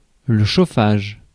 Ääntäminen
US : IPA : ['hiː.tɪŋ]